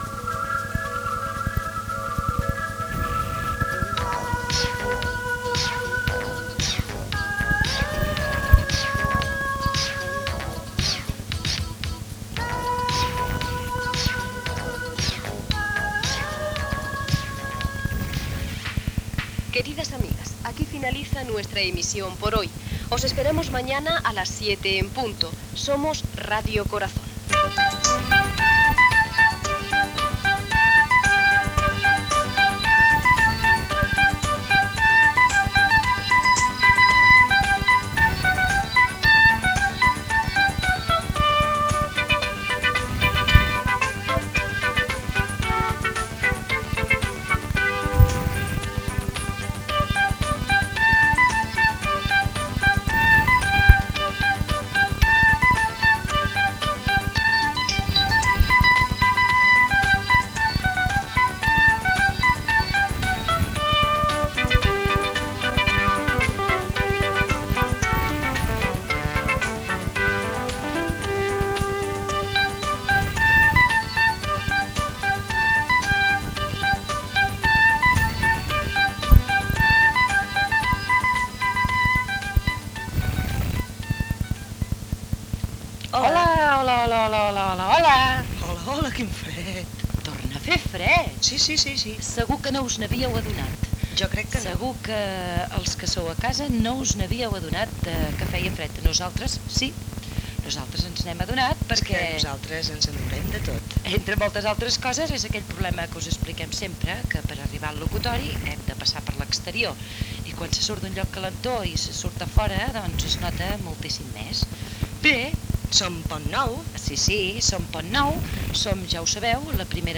Final de Radio Corazón, inici de Ràdio Pont Nou, amb salutació i santoral.
Infantil-juvenil
FM